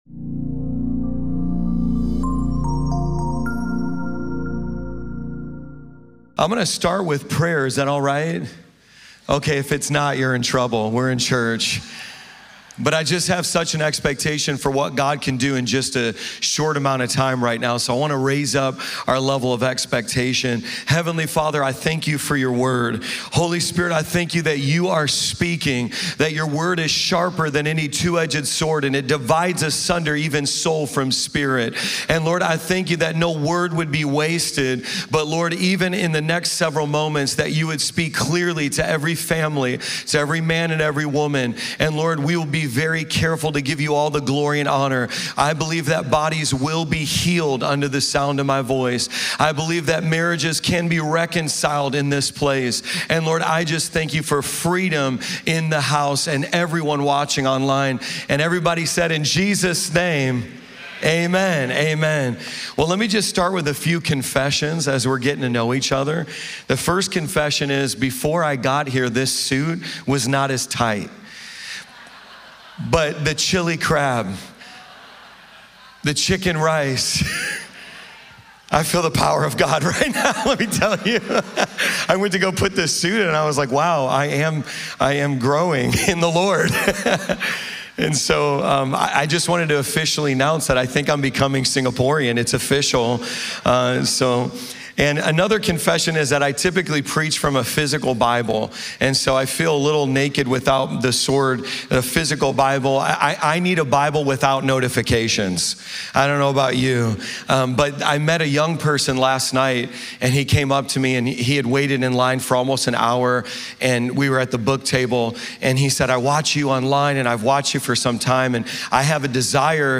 Weekly audio sermons from Cornerstone Community Church in Singapore